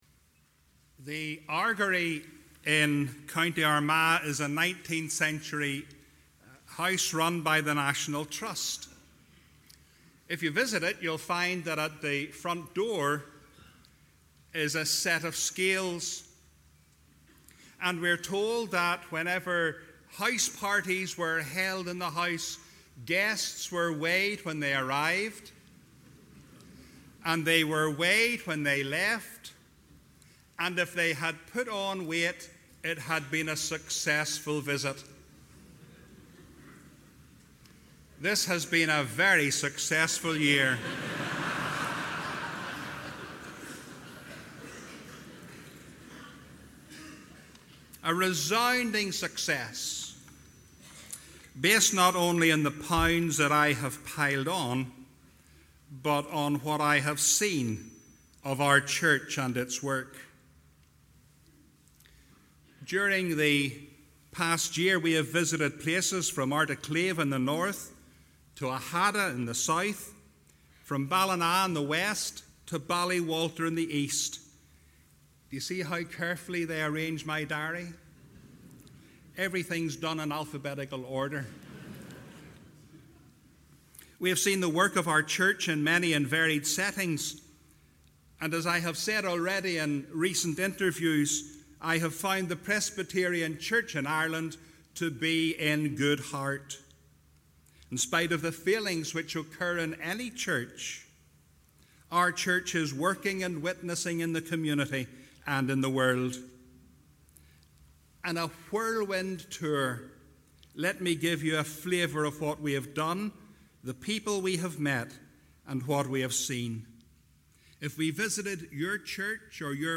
The outgoing Moderator, Dr Michael Barry, reflects on his year in office at the Opening Meeting of the 2015 General Assembly.